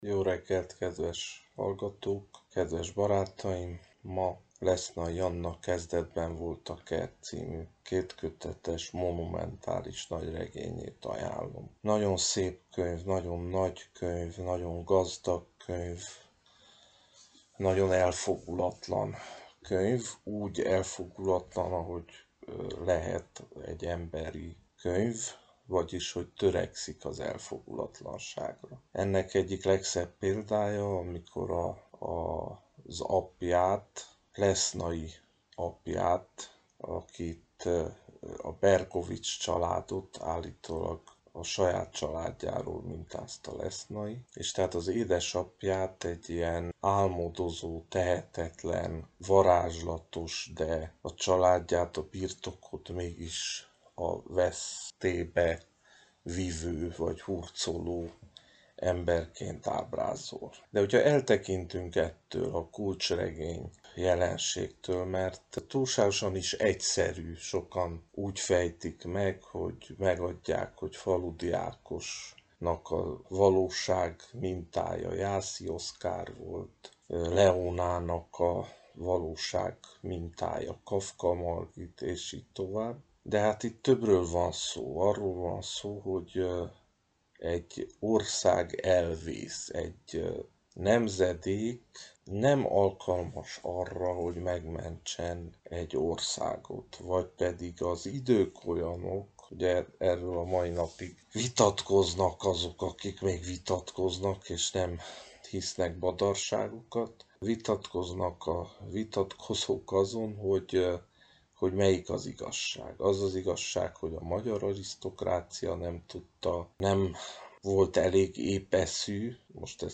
Klasszikus és kortárs művek egyaránt helyet kapnak ezekben az ajánlókban, ahogyan a magyar és a világirodalom palettájáról is segít nekünk válogatni az irodalmár.